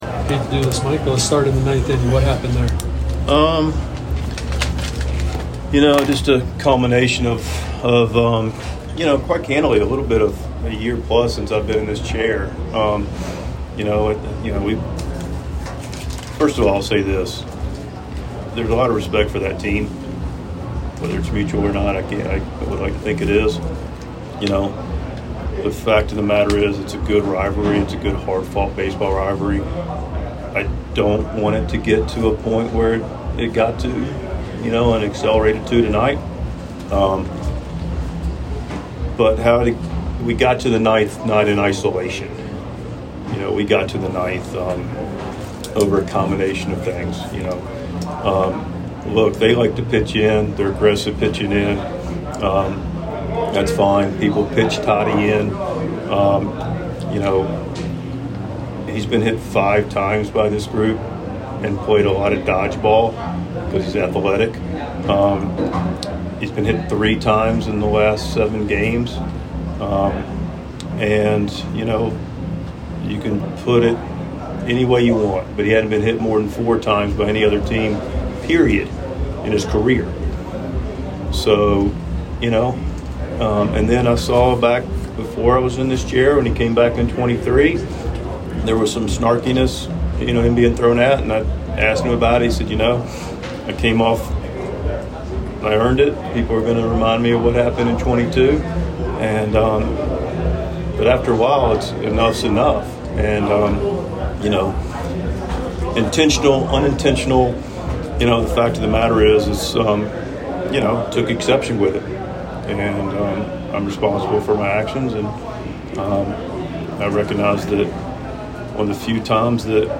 6.19.25 Mike Shildt Postgame Press Conference (Padres 5, Dodgers 3)
Padres manager Mike Shildt speaks to the media after the team's 5-3 win against the Dodgers on Thursday night.